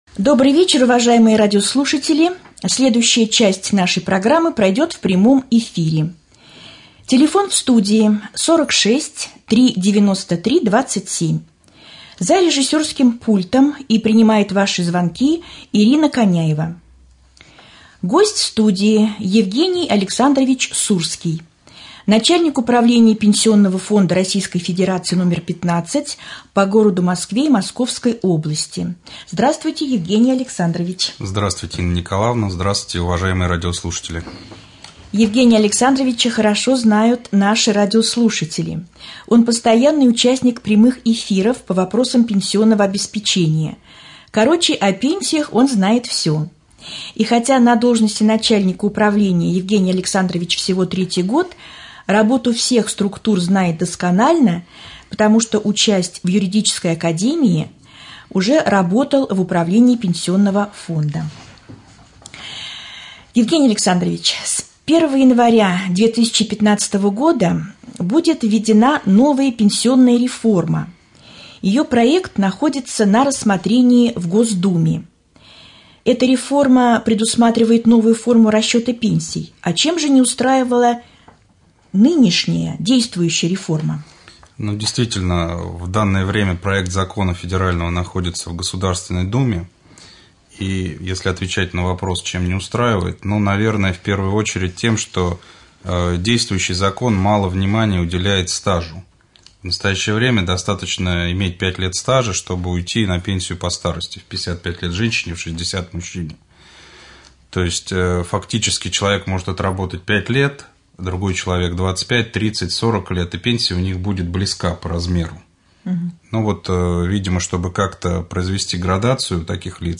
Прослушать: прямой эфир